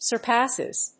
/sɝˈpæsɪz(米国英語), sɜ:ˈpæsɪz(英国英語)/